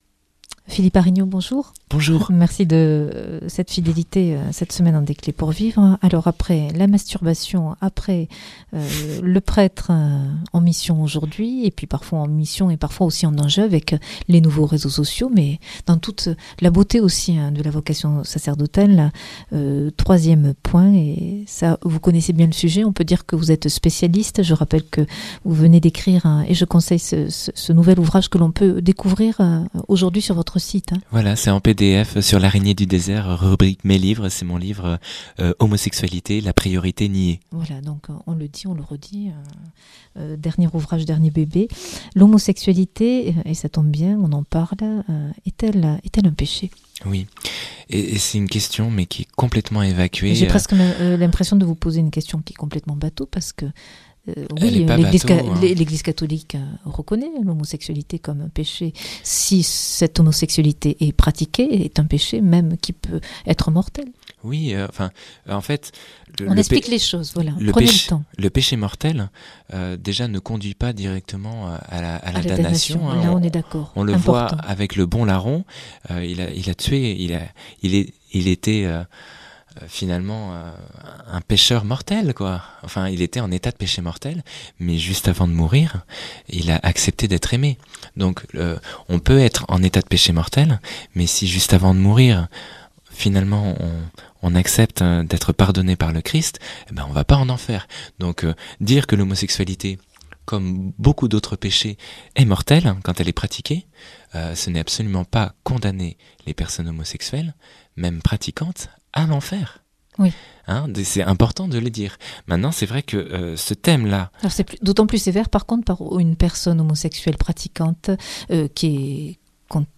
C’est un troisième entretien